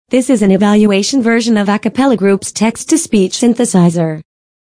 Voices list
Arabic (Saudi Arabia) Leila   HQ   F pdf      leila22k